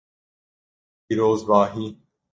Ler máis Significado (Inglés) celebration Traducións Beglückwünschung Glückwunsch celebration 🎉 kutlama tebrik Pronúnciase como (IPA) /piːɾoːzbɑːˈhiː/ Marcar isto como favorito Mellora a túa pronuncia Notes Sign in to write sticky notes